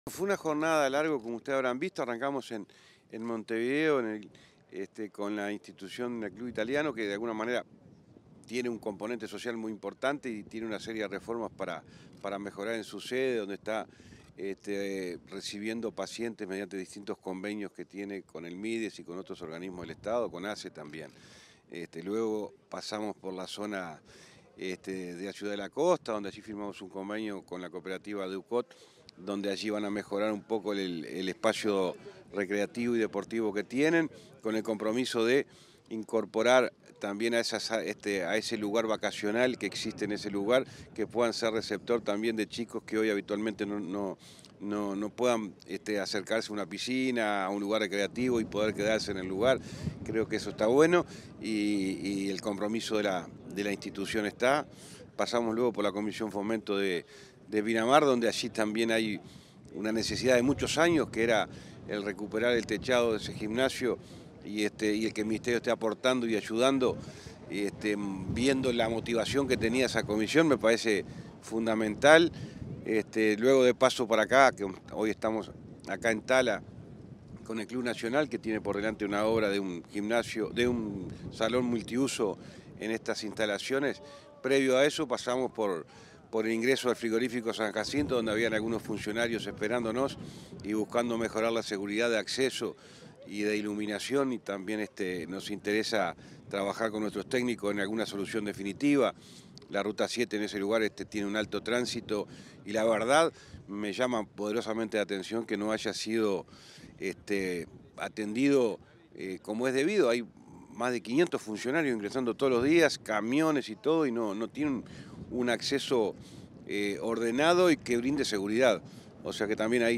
Entrevista al ministro de Transporte y Obras Públicas, José Luis Falero
Tras el evento, el ministro José Luis Falero brindó declaraciones a Comunicación Presidencial.